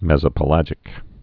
(mĕzə-pə-lăjĭk, mĕs-)